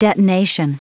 Prononciation du mot detonation en anglais (fichier audio)
Prononciation du mot : detonation